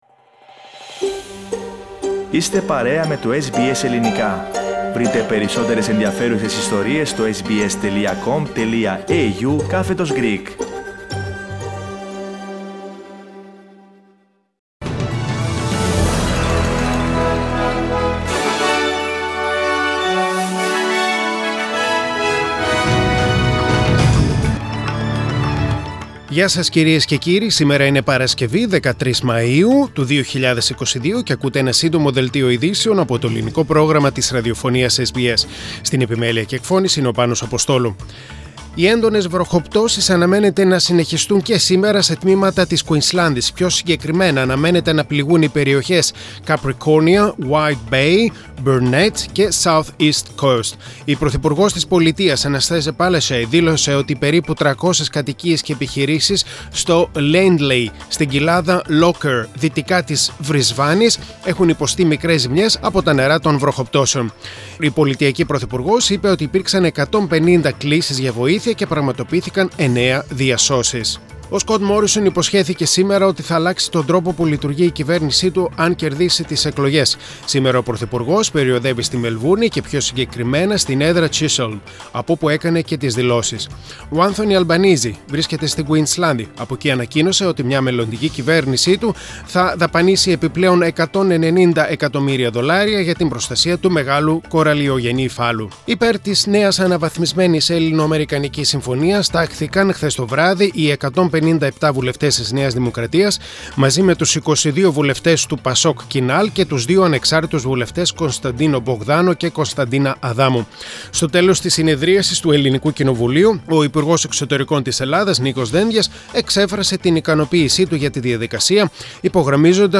Σύντομο Δελτίο Ειδήσεων στα Ελληνικά